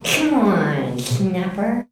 COMEONSNAP.wav